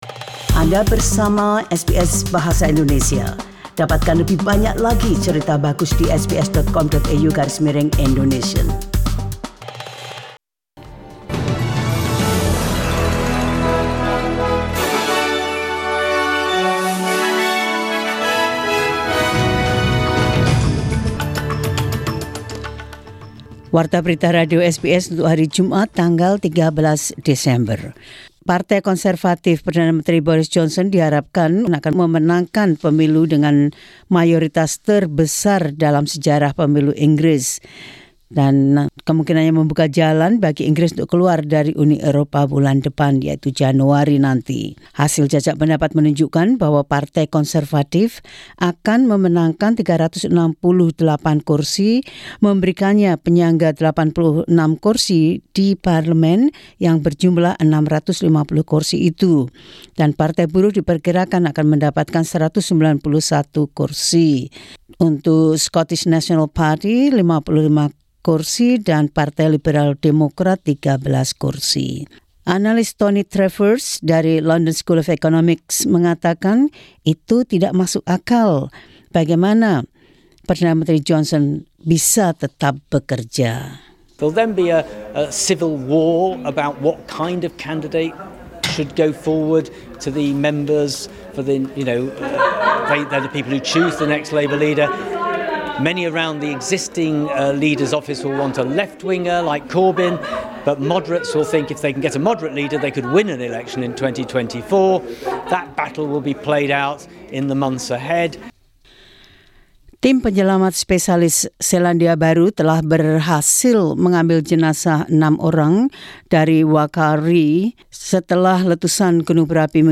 SBS Radio News in Indonesian 13 Dec 2019.